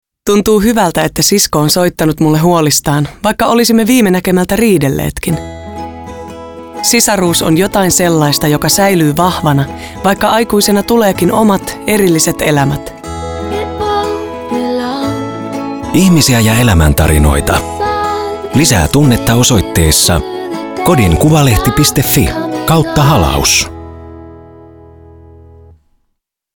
Kuuntele liikuttava radiomainoksemme.